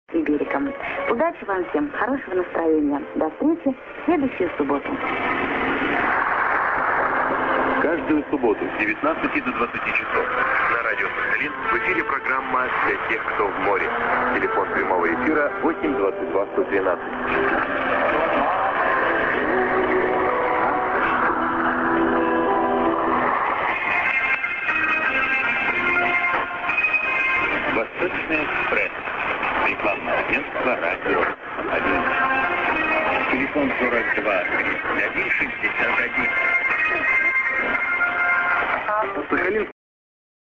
->ID(man)->　USB R.Sakharinsk(Radio Rossii)